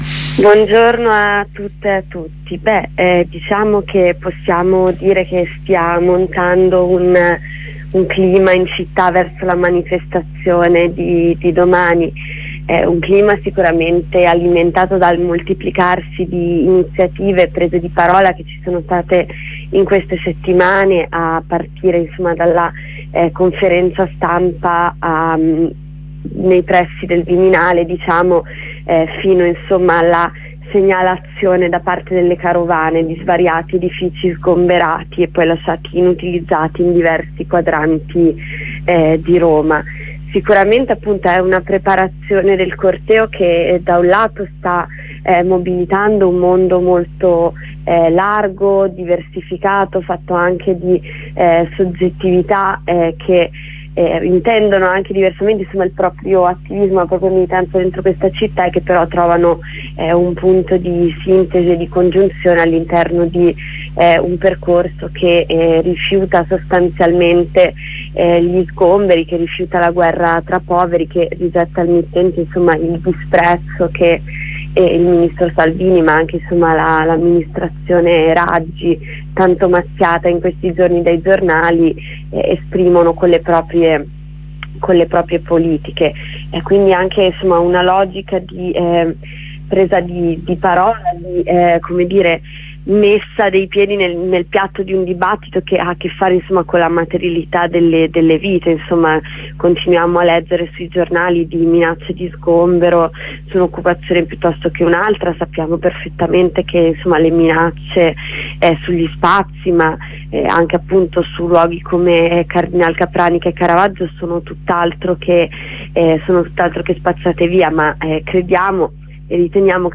L'intervento di Luigi Manconi